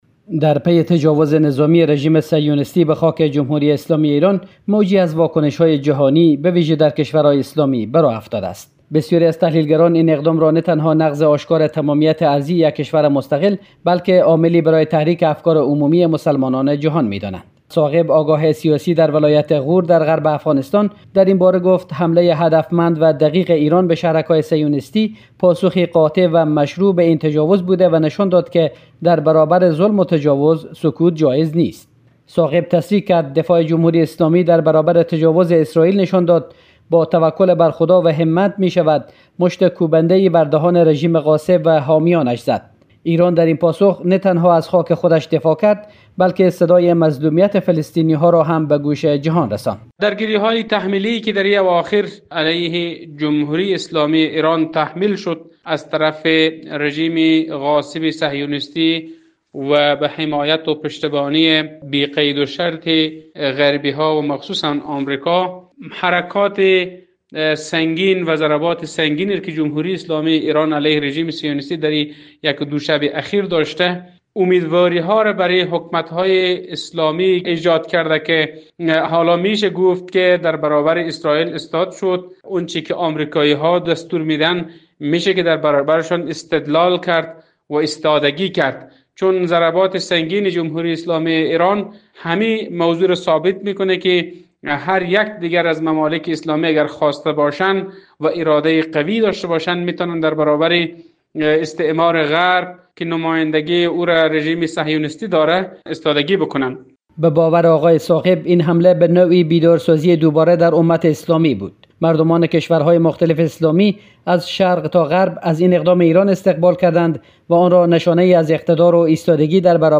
آگاه سیاسی افغان در مصاحبه